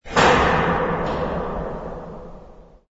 sfx_breaker.wav